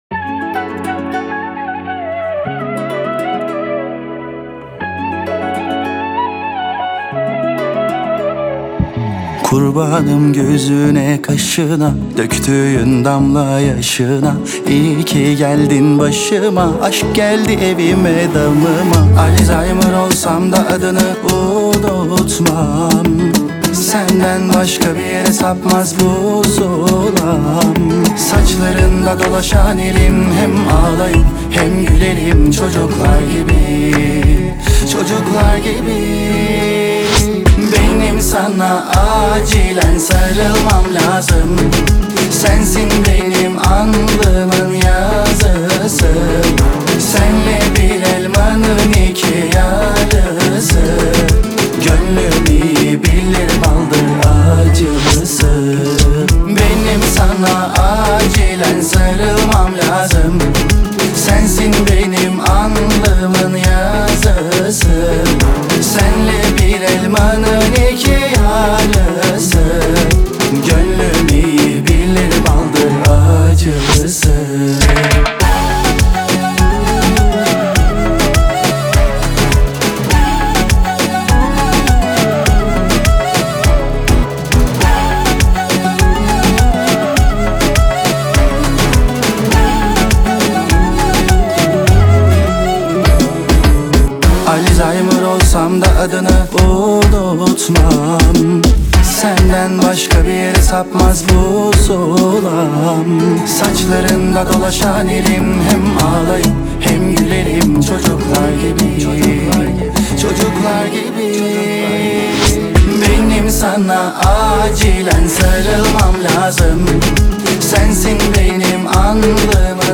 Трек размещён в разделе Турецкая музыка / Поп.